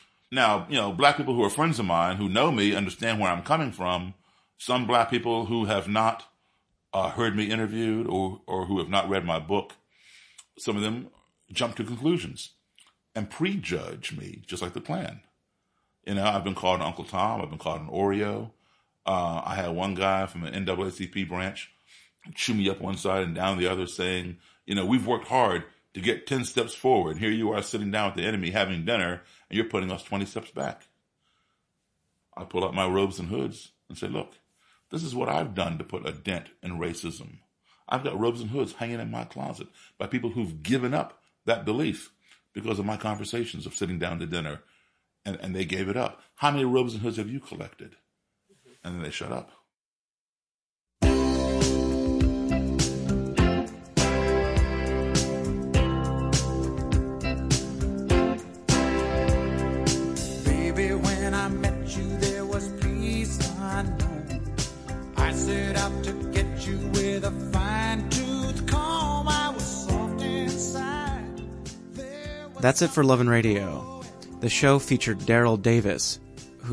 Since there is no host narration providing a critique, listeners could be forgiven for interpreting the lesson of Davis’ life story as being that racism is an individual rather than a systemic problem, and that the best way to effect positive social change is through personal action rather than any political initiative or collaborative effort.